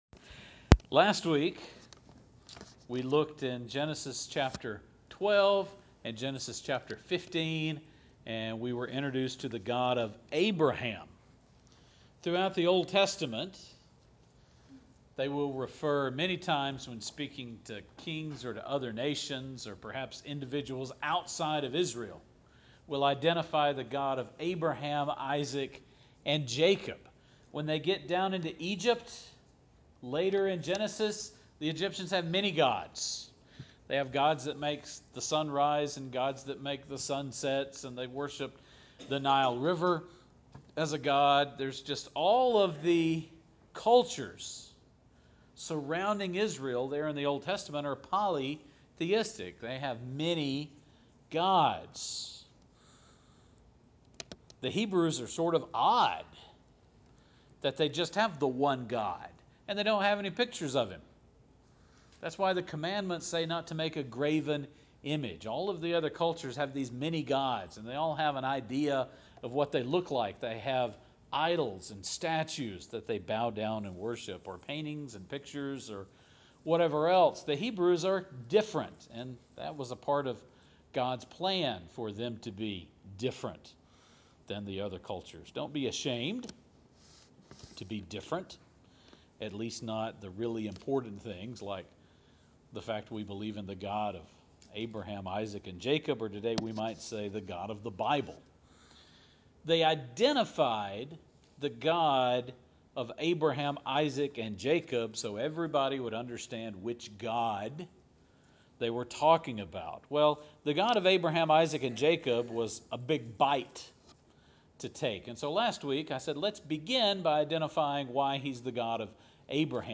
Last week we began with the God of Abraham, in Genesis chapters 12 and 15. In this sermon we will expand our field of view and see why future generations would identify God by his relationship with this family.